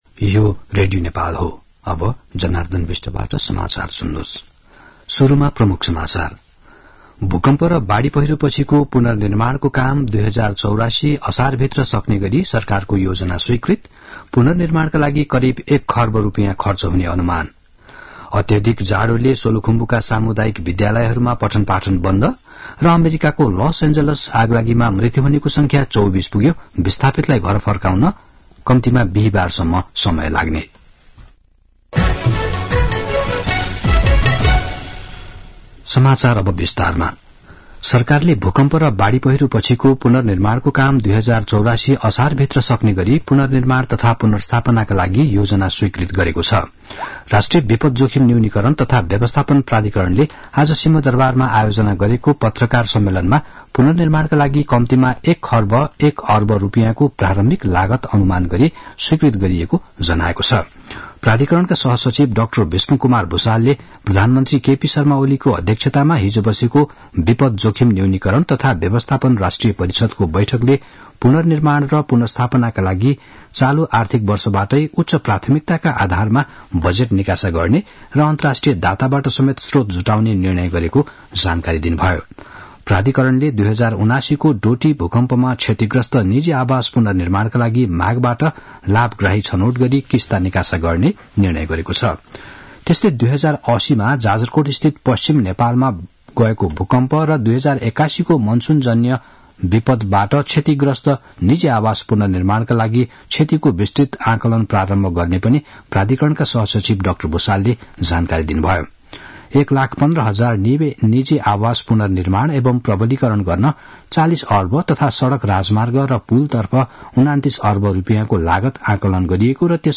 दिउँसो ३ बजेको नेपाली समाचार : १ माघ , २०८१
3-pm-news-1-4.mp3